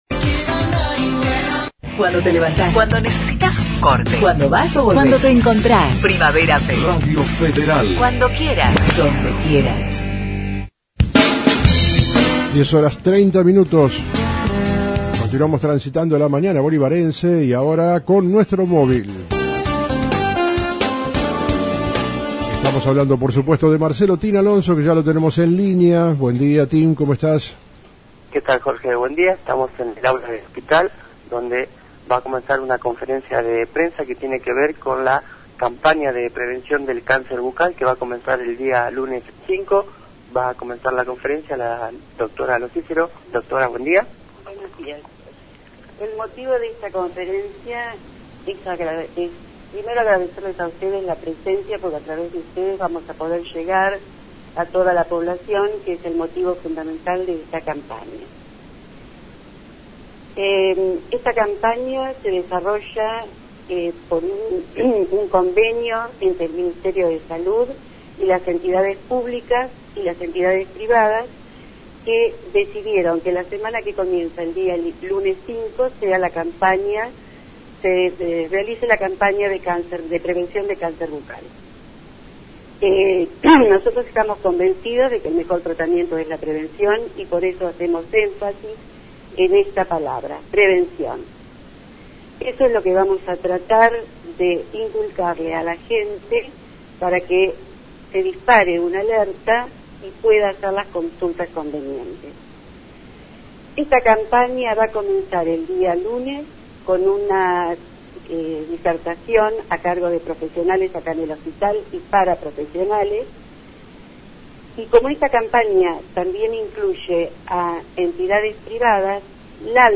Conferencia de Prensa Departamento de Odontologia del Hospital y el Circulo Odontologico.